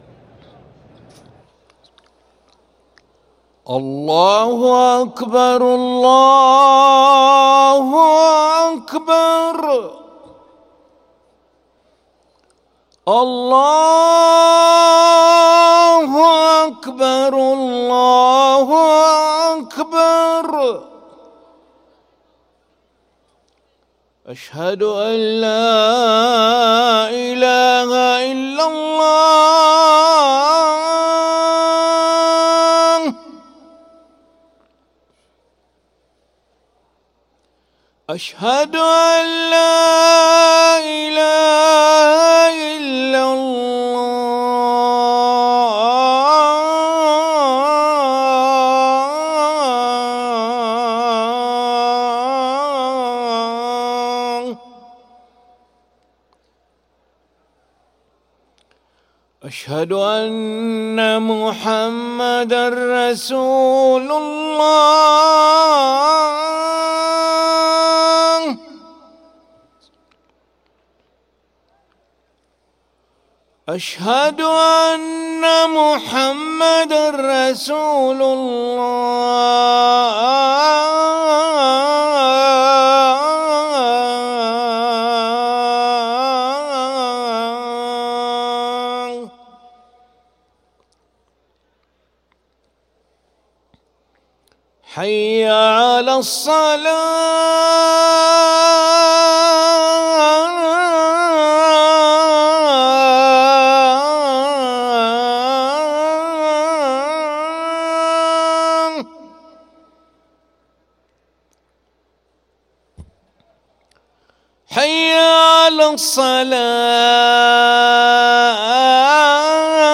أذان العشاء للمؤذن علي أحمد ملا الأحد 29 ذو القعدة 1444هـ > ١٤٤٤ 🕋 > ركن الأذان 🕋 > المزيد - تلاوات الحرمين